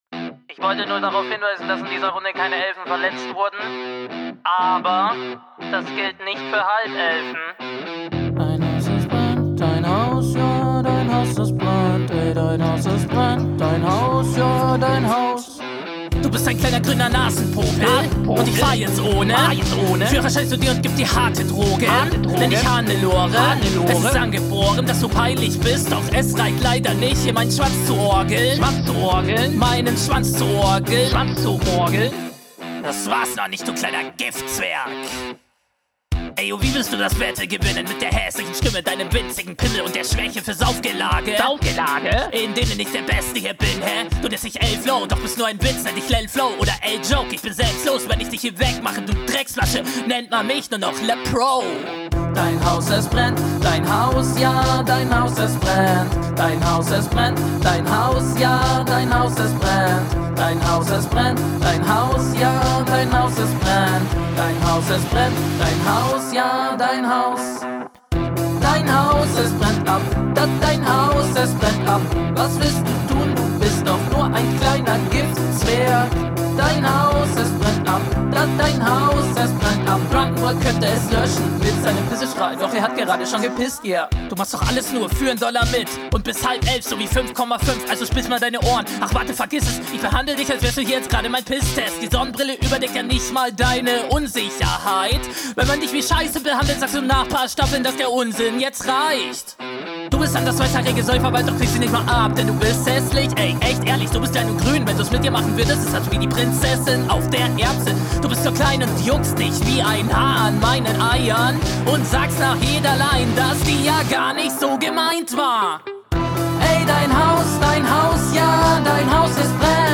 Stimme gefällt mir.
Hook nicht mein Fall und zu repetitiv.